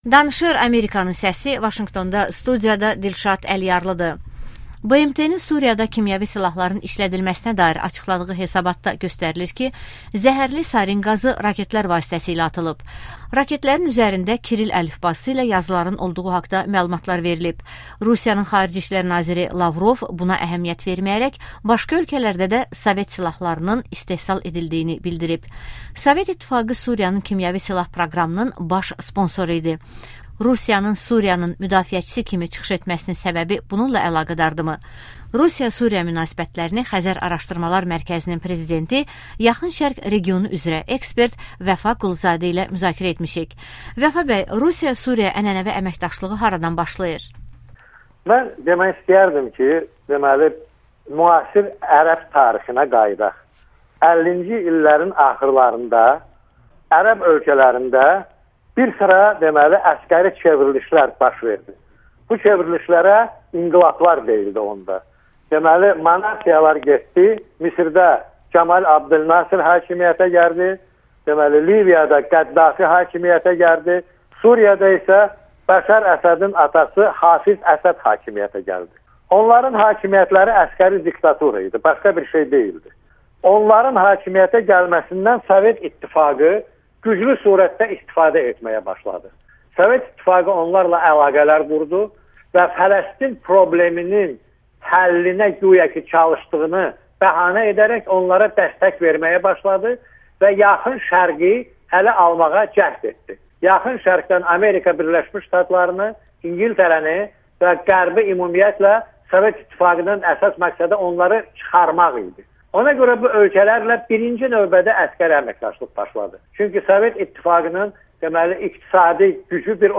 Vəfa Quluzadə ilə müsahibə